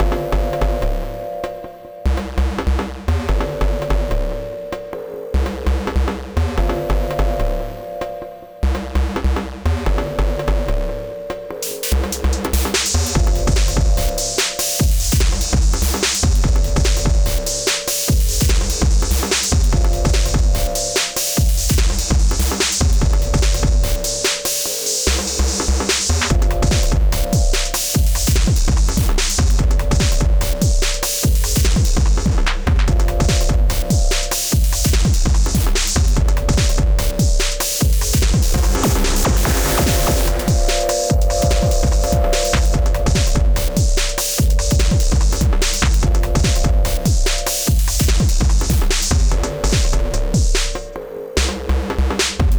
Ran a MD pattern into Ableton Live through some plugins (Soothe2, KClip Zero, Kelvin, Pro-C, Shadow Hills Class A).
One pattern, muting and un-muting channels, abusing control-all.
Nice sounds any way :slight_smile: